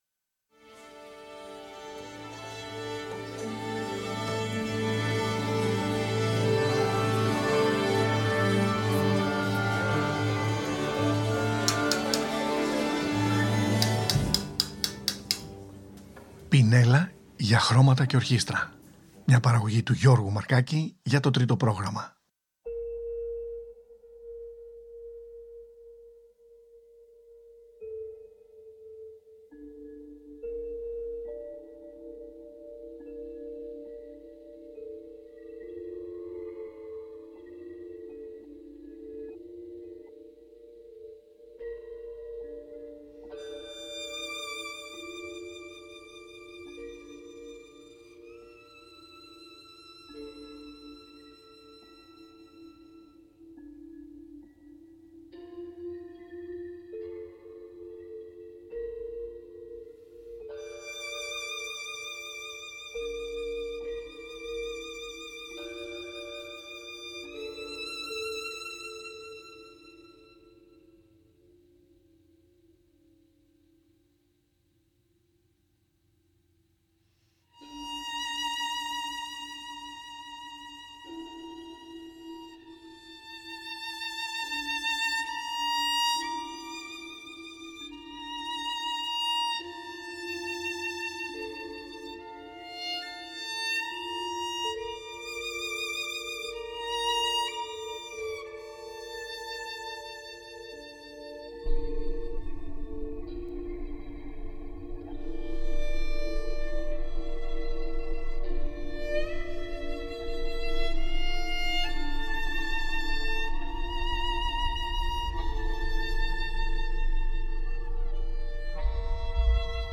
Η καταξιωμένη βιολονίστα Anne Akiko Meyers συνεργάζεται με την Philharmonia Orchestra και ερμηνεύει ένα ενδιαφέρον έργο του νεαρού Πολωνού πολυμήχανου συνθέτη Jakub Ciupinski που άλλοτε συνθέτει μουσική για χορογραφίες και θεατρικές παραστάσεις με έργα που συνορεύουν με το κλασικό ρεπερτόριο και άλλοτε κυκλοφορεί τα έργα του με ηλεκτρονική μουσική για διαφορετικό κοινό με το καλλιτεχνικό όνομα Jakub Zak.